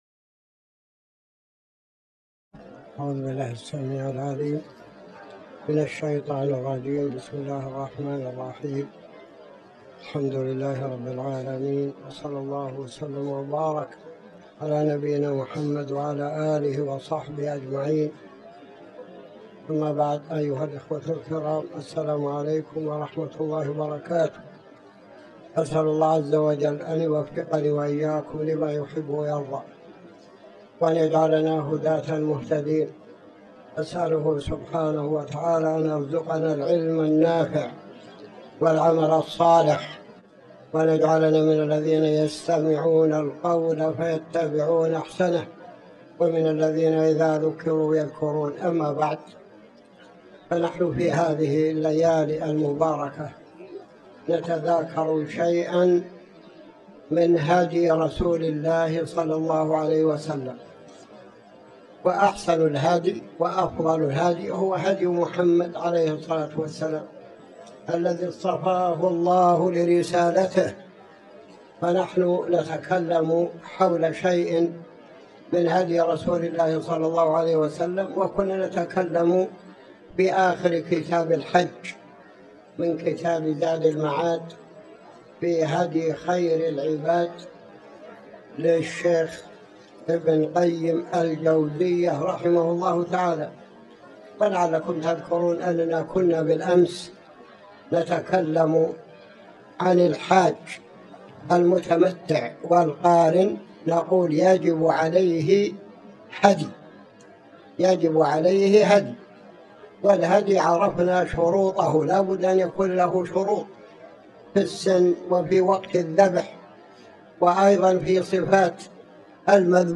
تاريخ النشر ١٤ جمادى الأولى ١٤٤٠ هـ المكان: المسجد الحرام الشيخ